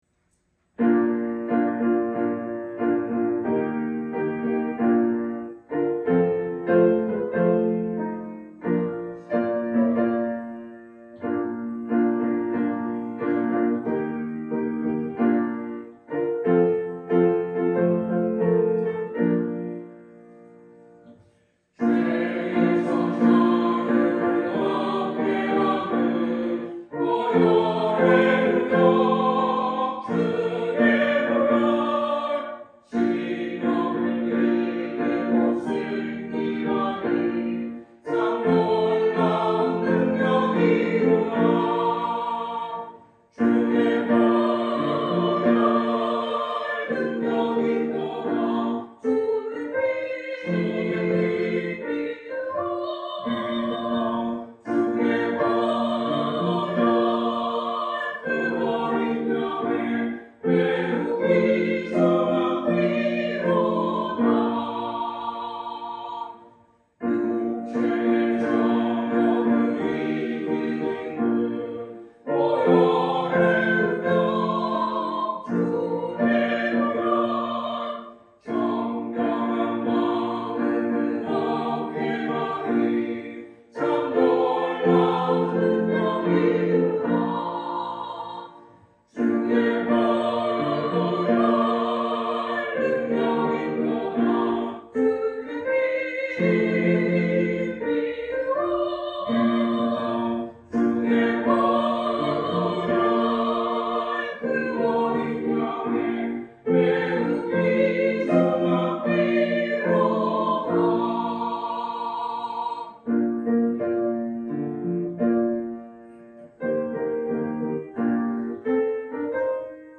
성가대